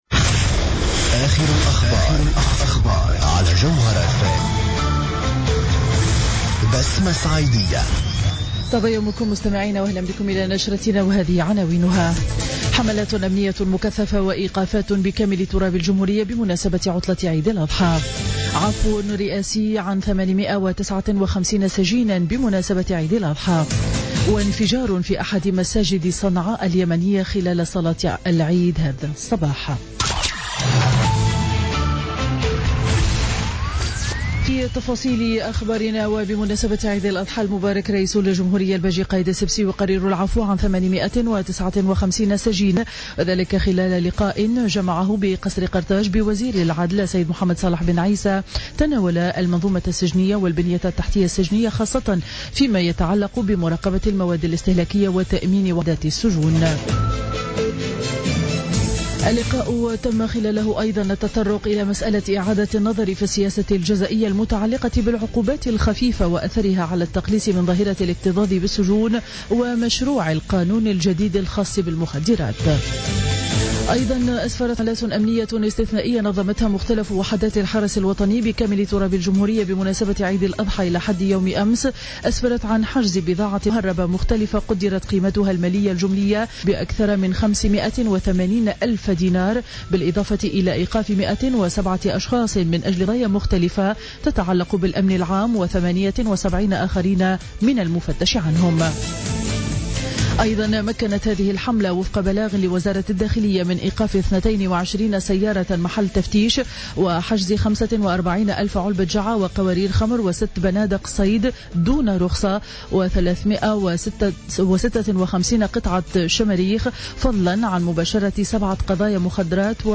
نشرة أخبار السابعة صباحا ليوم الخميس 24 سبتمبر 2015